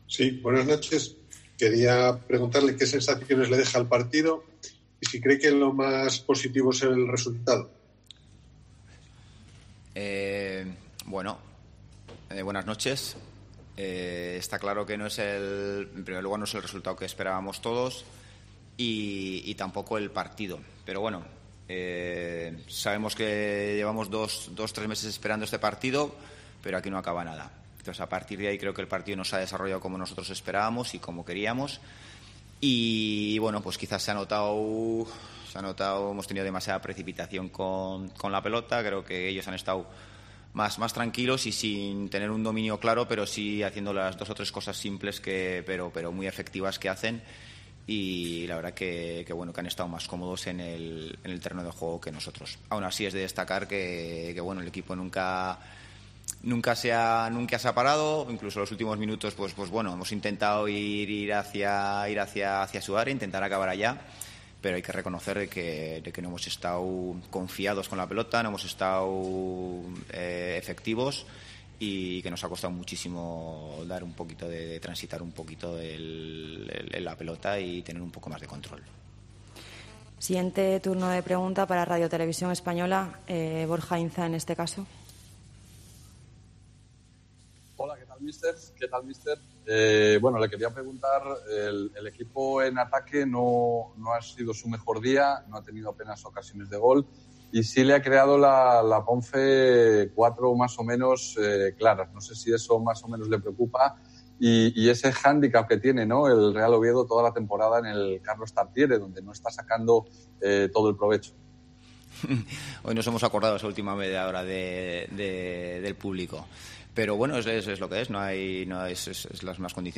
POSTPARTIDO
Escucha aquí al entrenador del Real Oviedo tras el empate 0-0 ante la Ponferradina